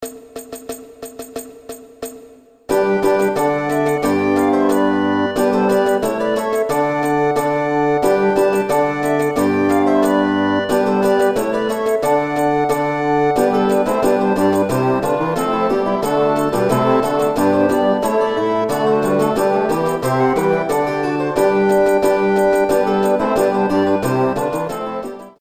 Pommern